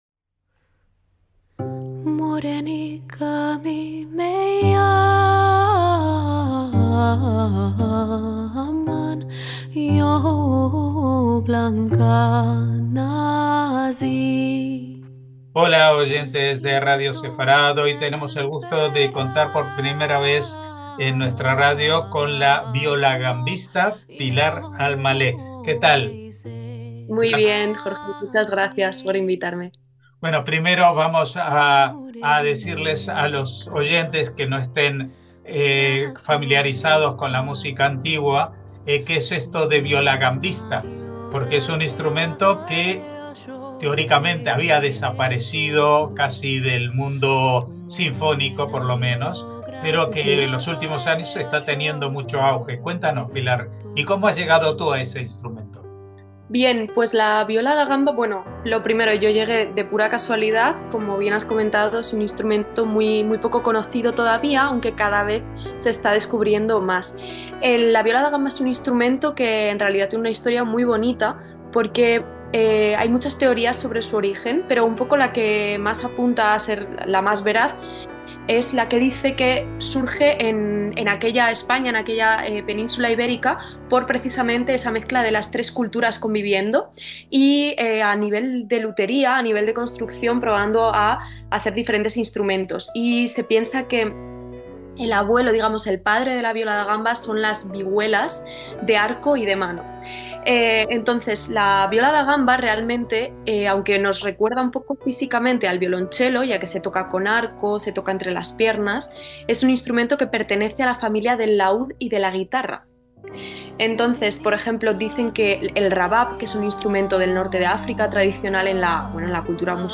viola da gamba y música sefardí
LA ENTREVISTA